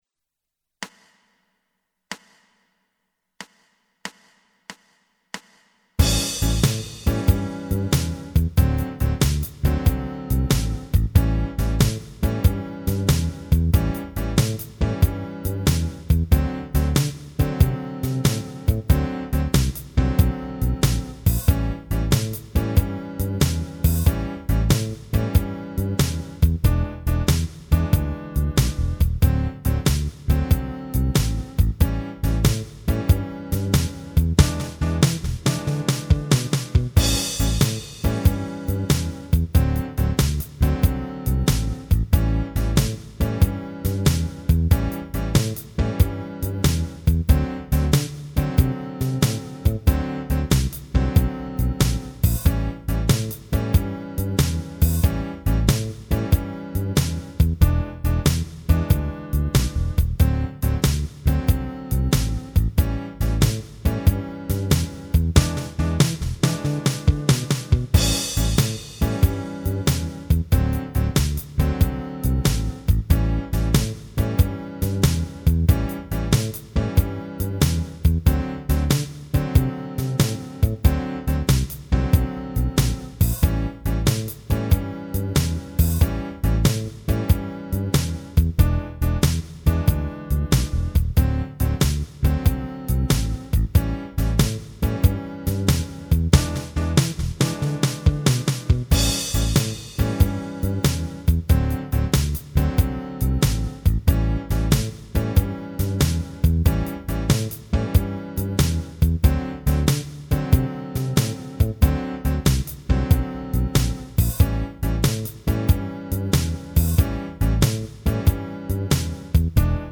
Dopo tutta la serie di fraseggi singoli possiamo vedere come questi si comportano all'interno di un chorus blues in A. Non sono presenti particolari situazioni che siano già state comunque analizzate.
singola base, utile quest'ultima per sperimentare le vostre improvvisazioni.